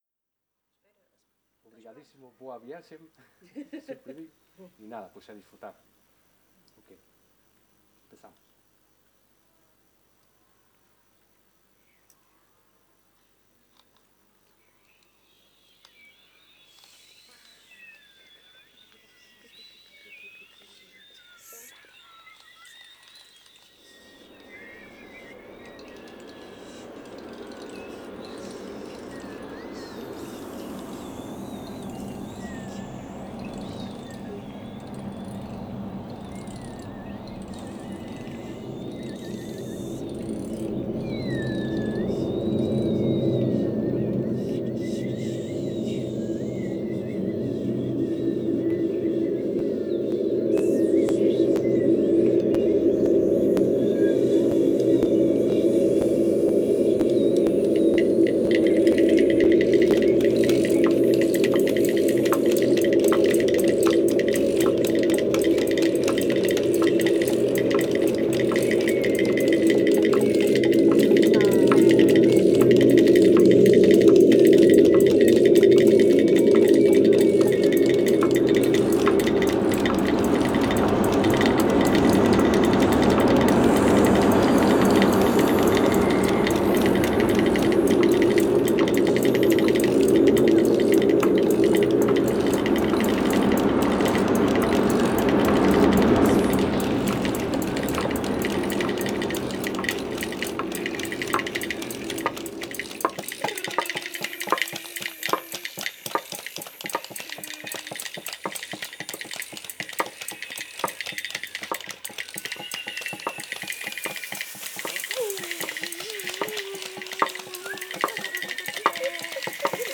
Grupo Etnográfico de Trajes e Cantares do Linho
A vida do jogador (Versão 2) (Várzea de Calde, Viseu)